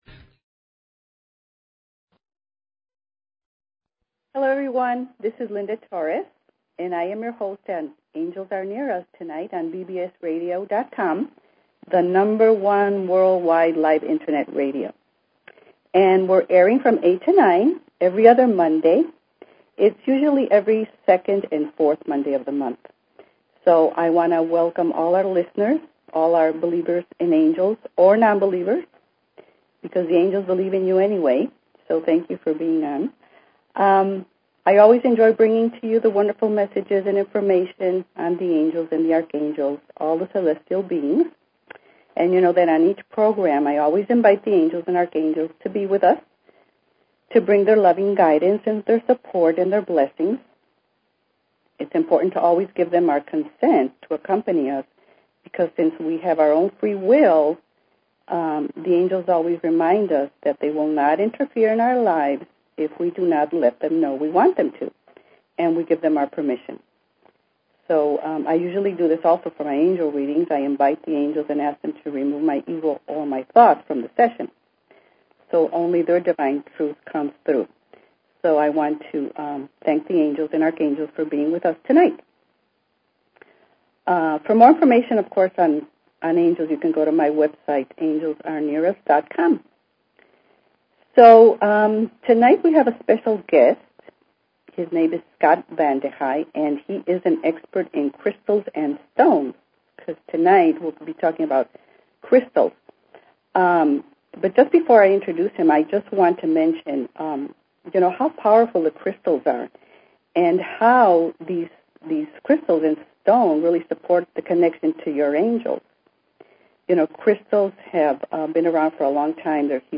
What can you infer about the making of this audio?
The last 30 minutes of the show the phone lines will be open for questions and Angel readings.